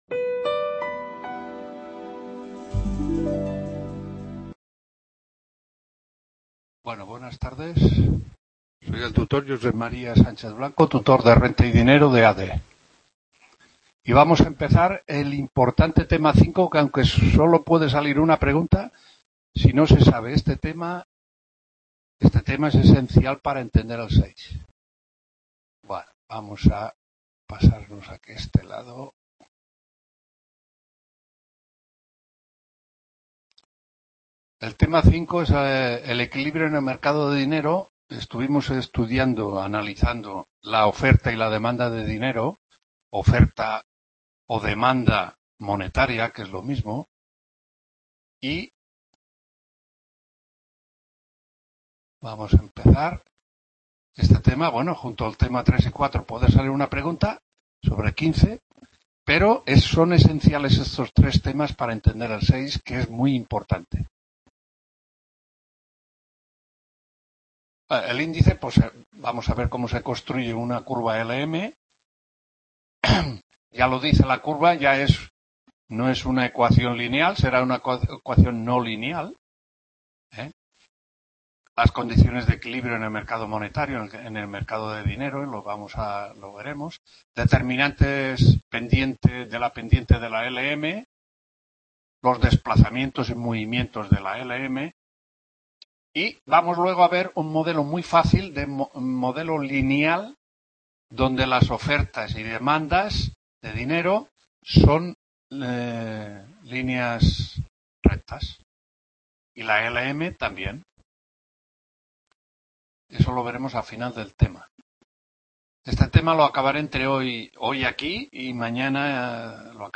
9ª TUTORÍA (1ª PARTE) LA CURVA LM 4-5-21 RENTA Y…